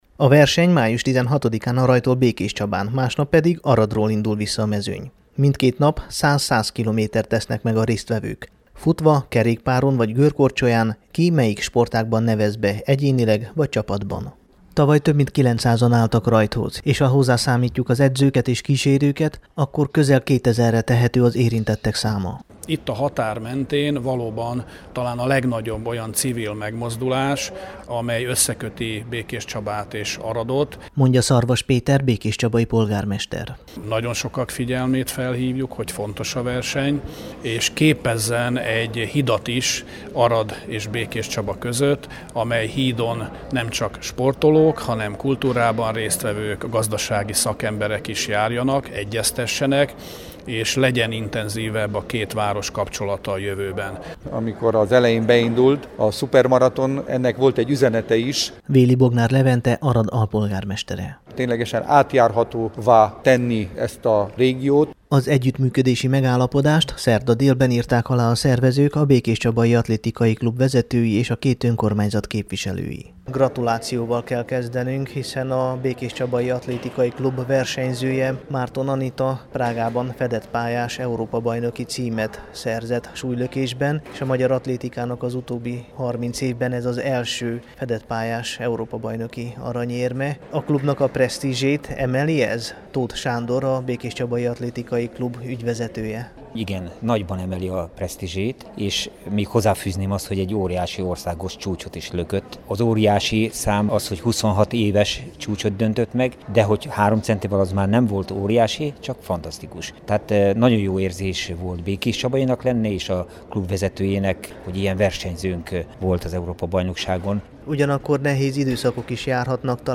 18-ik_szupermaraton_sajtotaj.mp3